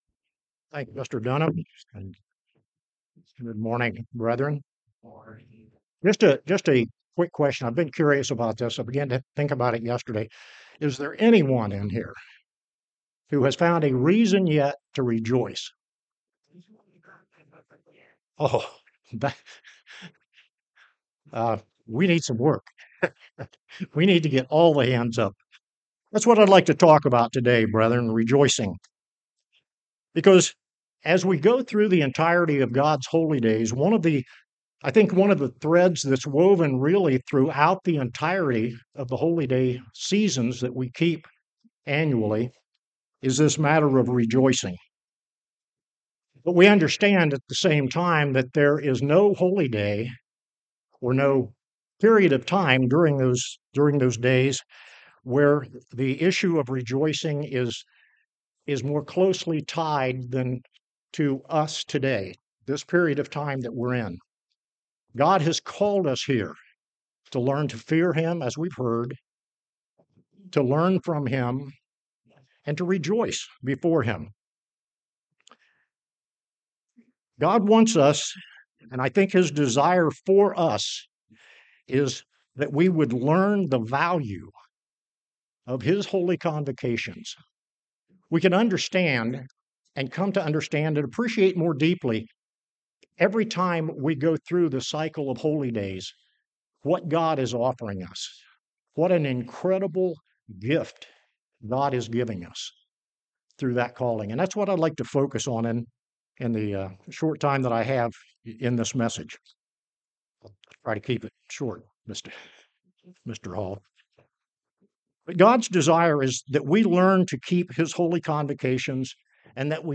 This sermon was given at the Cincinnati, Ohio 2024 Feast site.